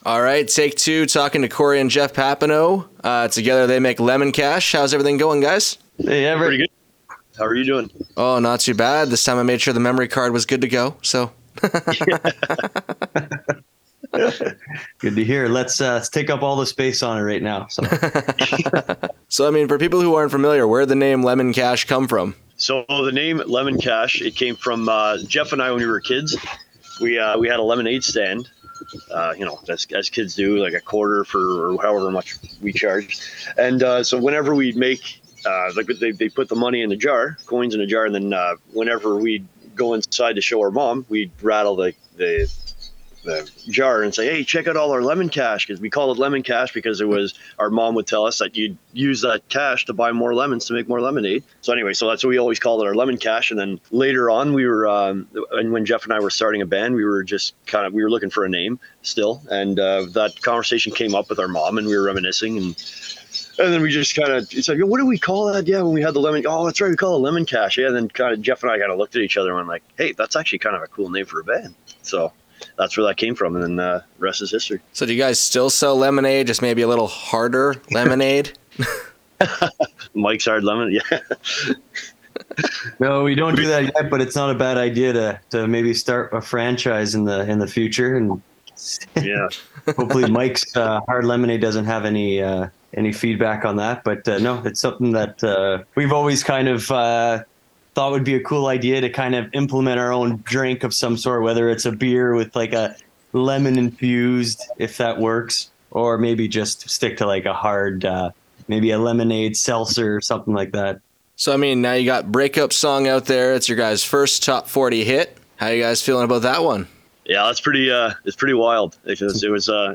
Lemon Cash Interview
Here is the full conversation with Lemon Cash:
Lemon-Cash-On-air.wav